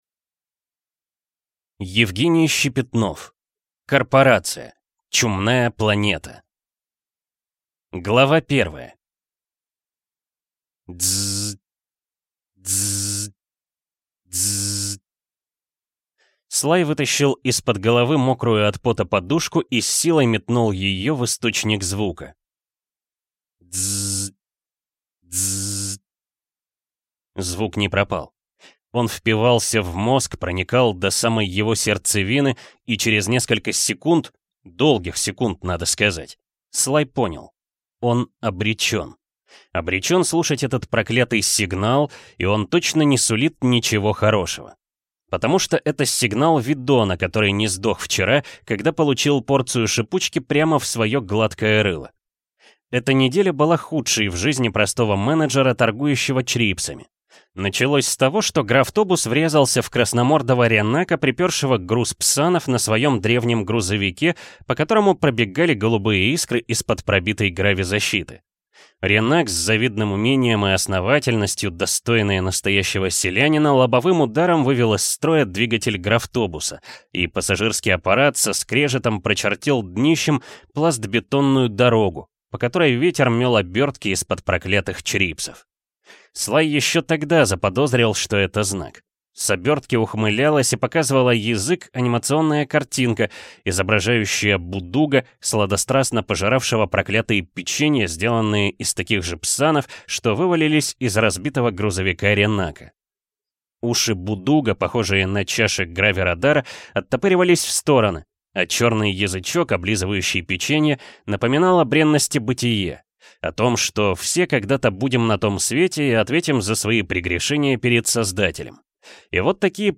Аудиокнига Корпорация. Чумная планета | Библиотека аудиокниг